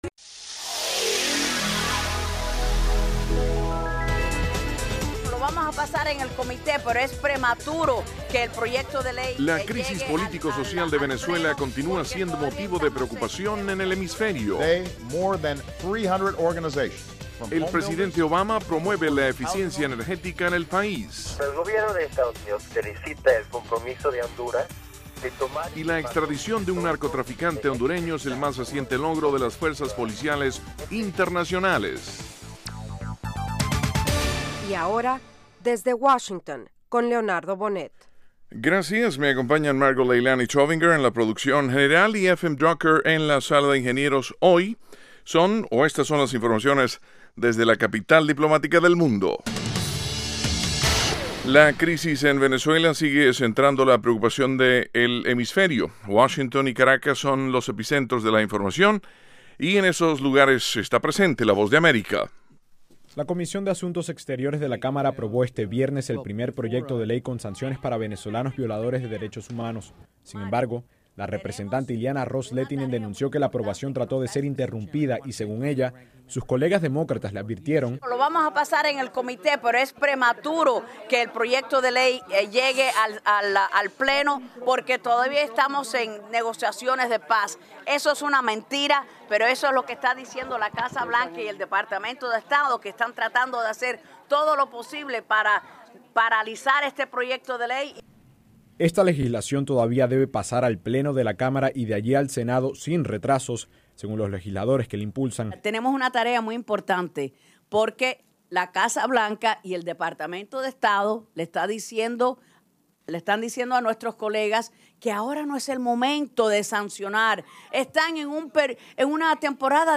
Con entrevistas a líderes políticos, nacionales y extranjeros, Desde Washington ofrece las últimas noticias sobre los acontecimientos que interesan a nuestra audiencia. El programa se transmite de lunes a viernes de 8:00 p.m. a 8:30 p.m. (hora de Washington).